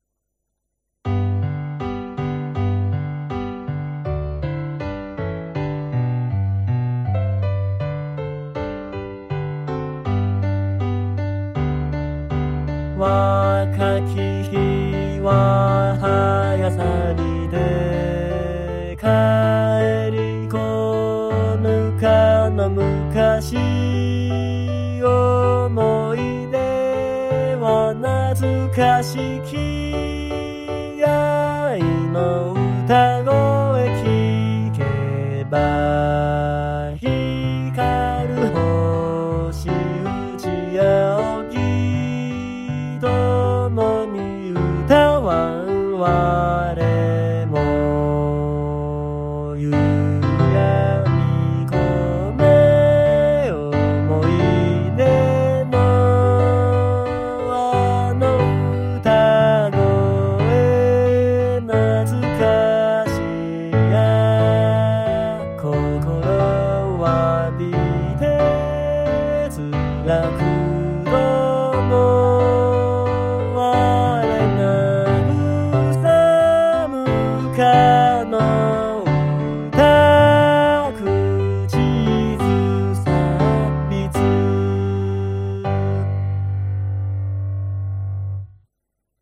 Gakupoidの音声付きです。